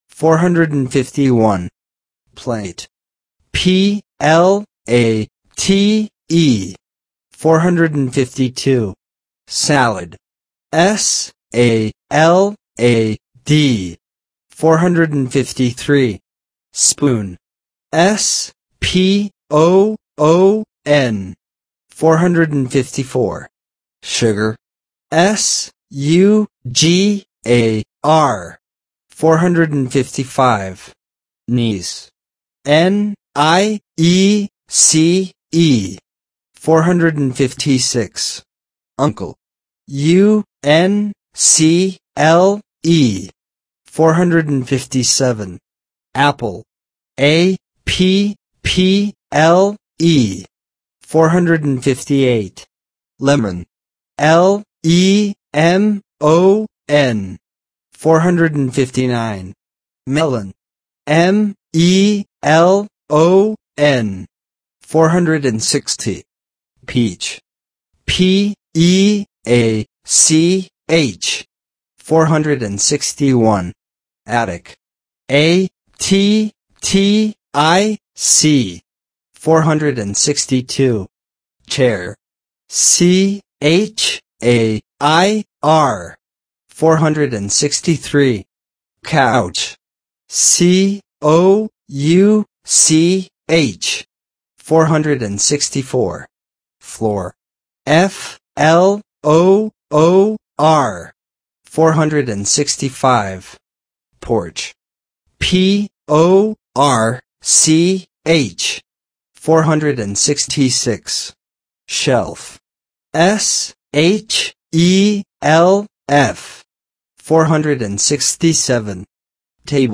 Spelling Exercises
401 – 450 Listen and Repeat